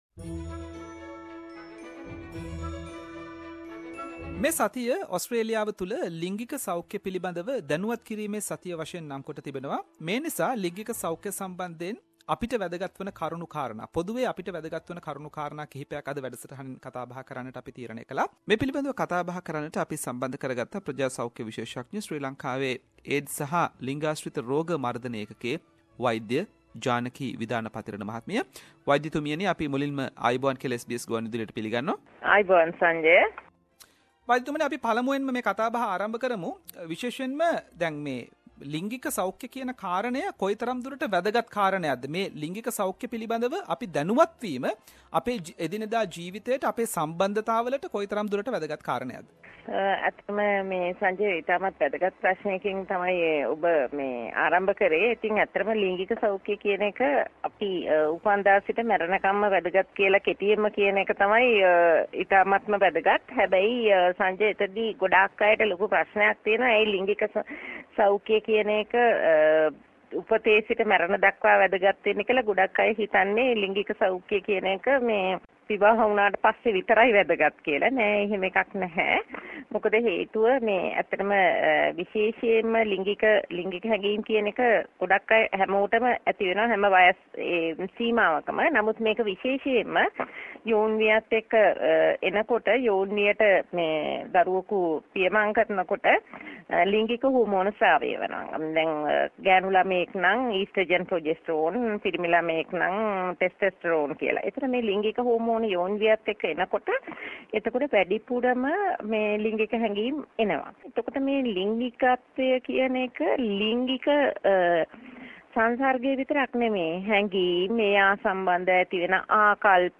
Sexual Health Awareness Week is about answering common questions about sexual health and broadening our understanding of sexual health issues. SBS Sinhalese interviewed community health physician